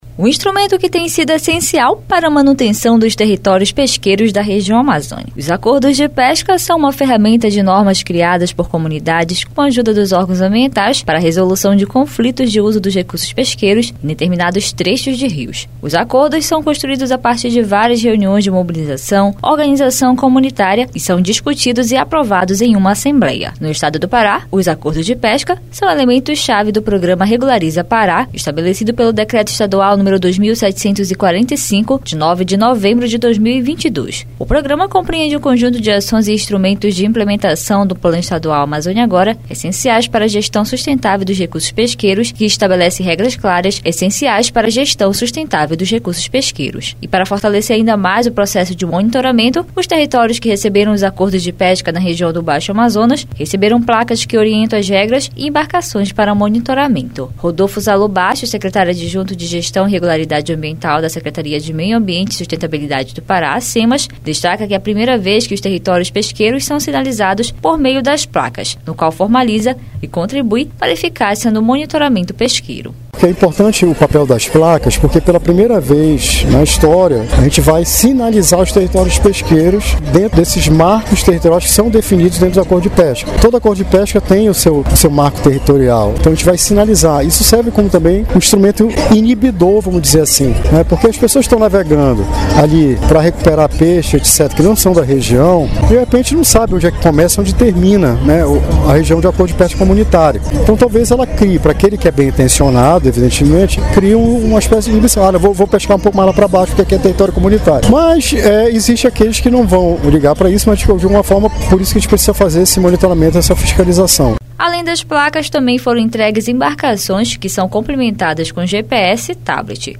Placas orientam regras em áreas protegidas por acordo de pesca no Baixo Amazonas. Além das placas também foram entregues embarcações com GPS e tablet que devem auxiliar no mapeamento de conflitos. A reportagem